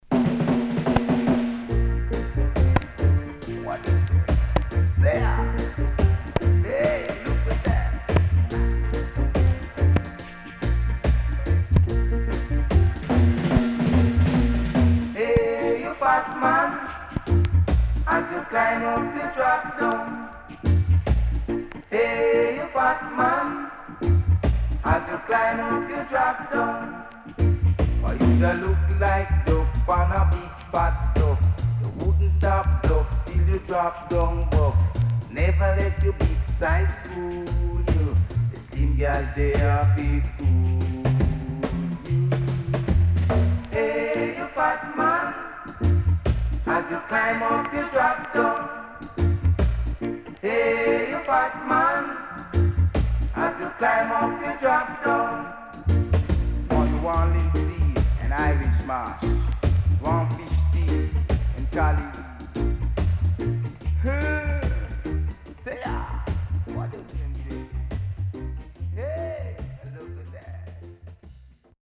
Very Cool & Deep Deejay Album